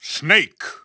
The announcer saying Snake's name in English and Japanese releases of Super Smash Bros. Brawl.
Snake_English_Announcer_SSBB.wav